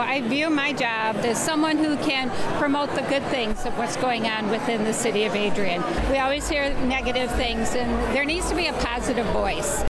In her acceptance speech, Roberts thanked her fellow city officials and credited her work on City Commission for inspiring her to get more involved with the business community. She also noted that positive civic engagement is a cornerstone of her service philosophy.
That was Adrian City Commissioner Mary Roberts.